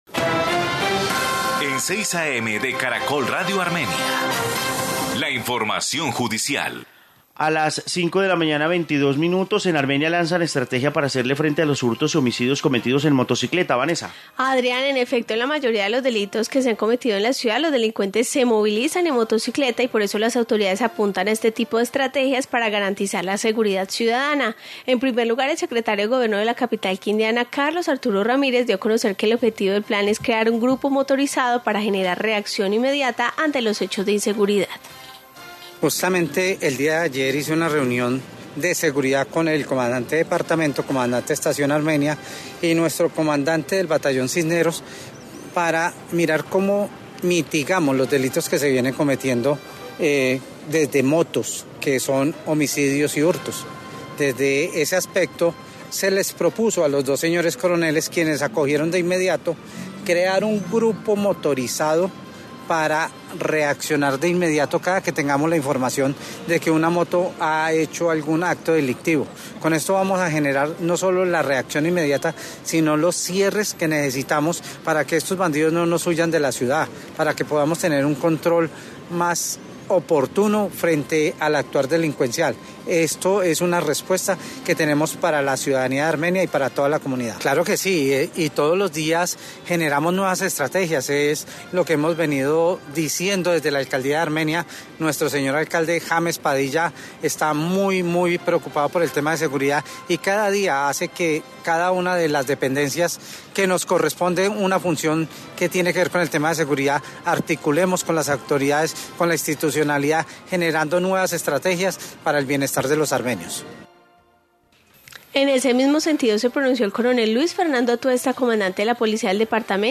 Informe sobre estrategia de seguridad